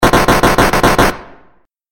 دانلود آهنگ نبرد 13 از افکت صوتی انسان و موجودات زنده
جلوه های صوتی
دانلود صدای نبرد 13 از ساعد نیوز با لینک مستقیم و کیفیت بالا